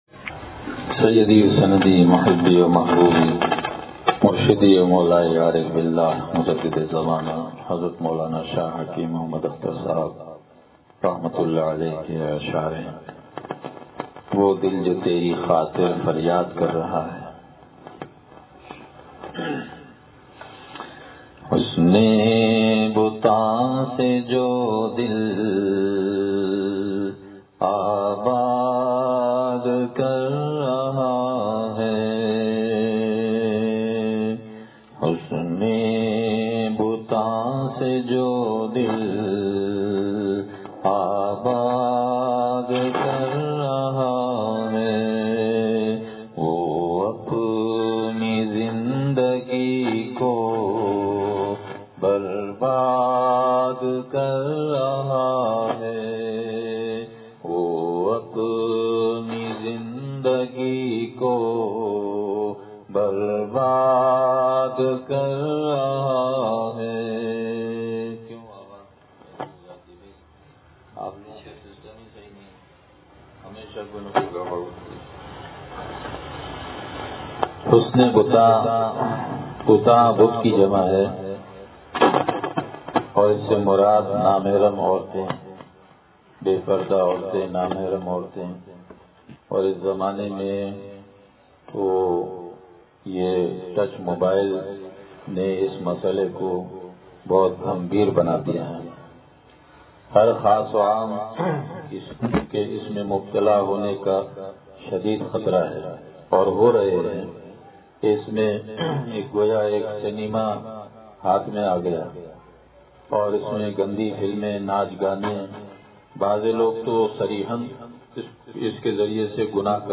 بیان